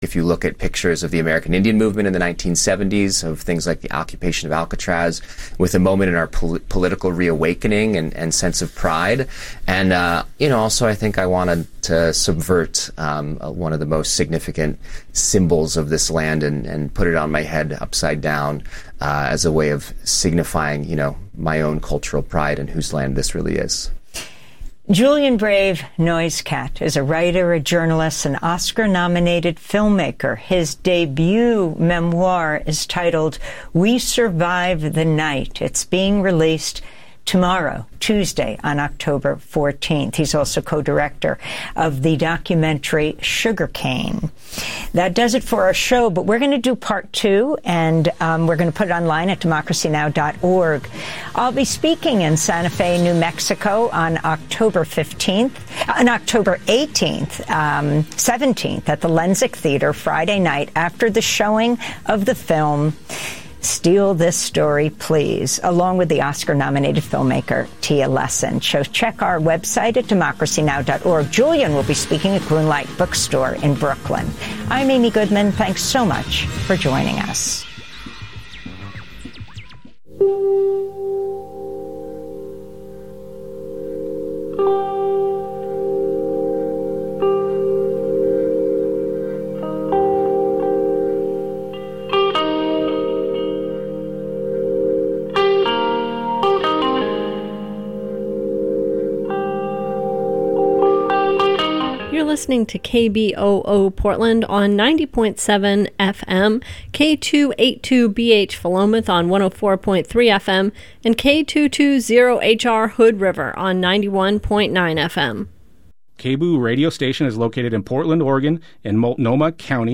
Evening News on 10/13/25
Non-corporate, community-powered, local, national and international news